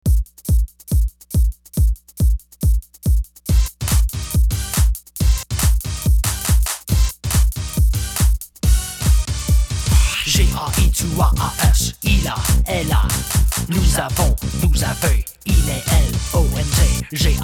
hip-hop song about possessive adjectives.